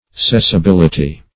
-- Ces`si*bil"i*ty , n. [Obs.]